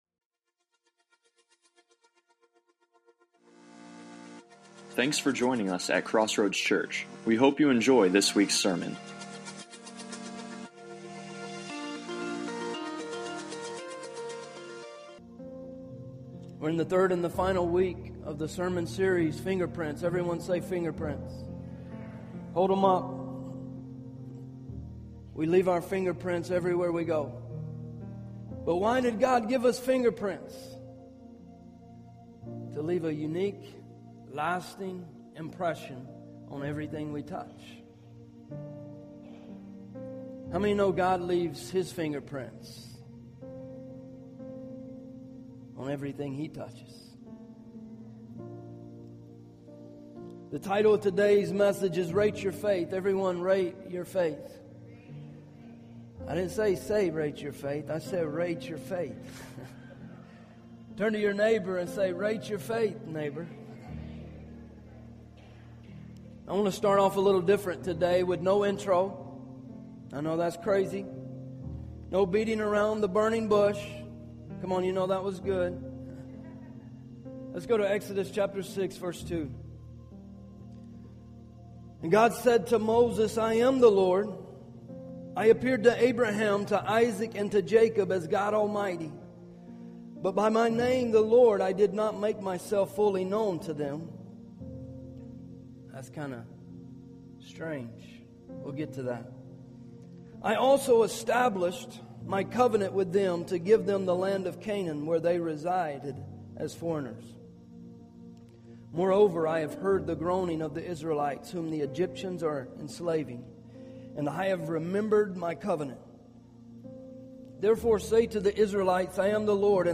Sermons - Crossroads Church